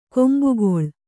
♪ kombugoḷ